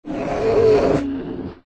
An Alpha Thrumbo using it's Terror roar ability in Odyssey
Alpha-thrumbo-terror-roar.mp3